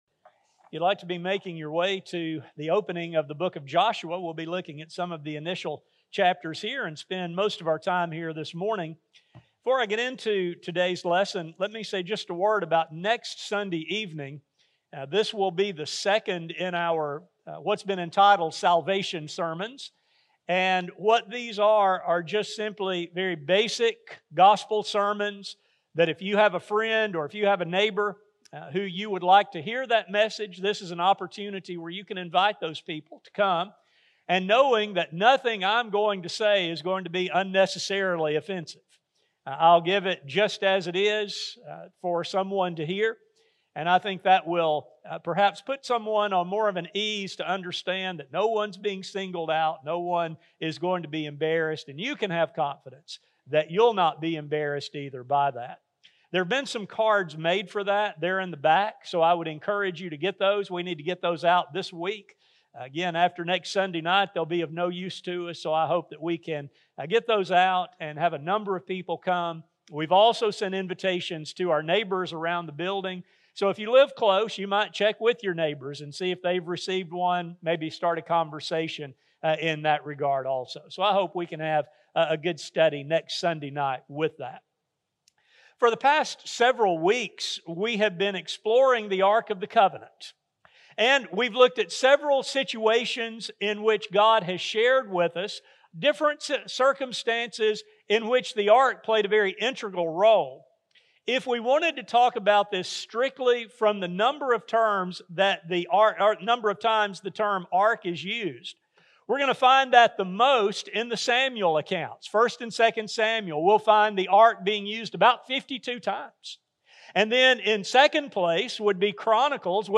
This lesson explores the early chapters of the book of Joshua to not only see the role of the Ark but to learn important lessons from this ancient account. A sermon recording